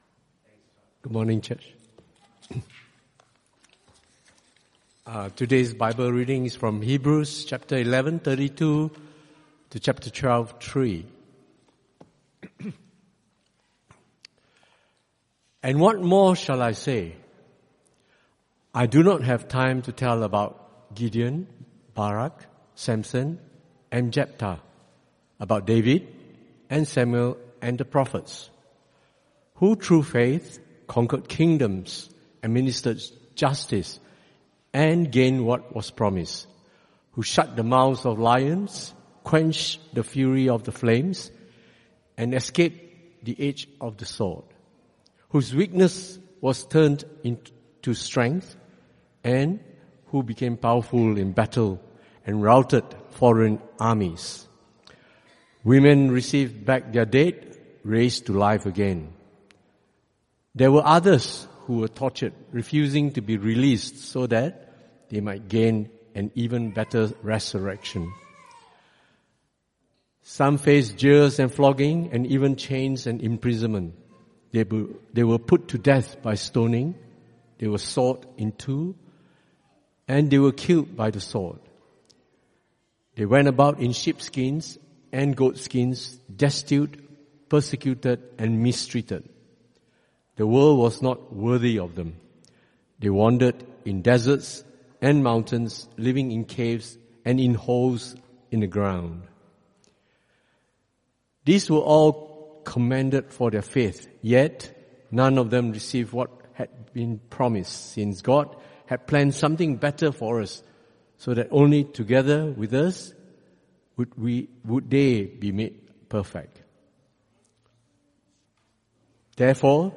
Passage: Hebrews 11:32-12:3 Type: Sermons